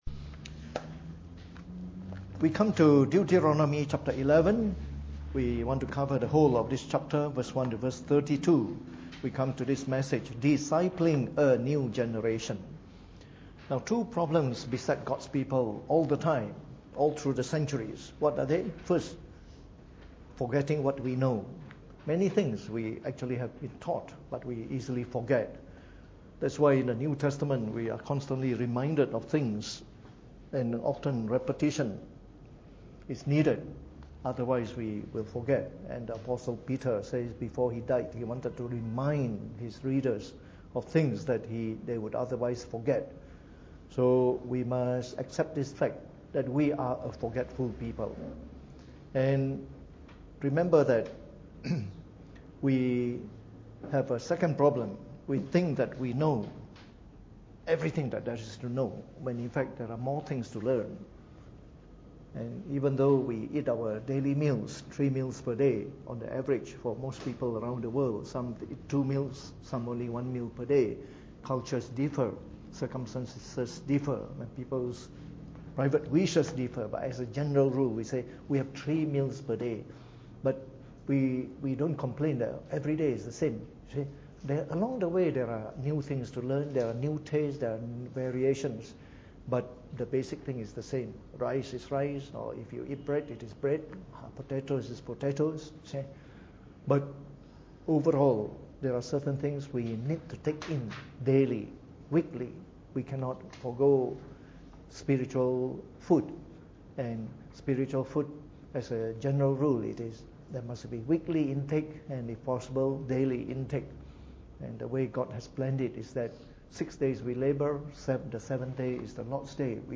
Preached on the 11th of April 2018 during the Bible Study, from our series on the book of Deuteronomy.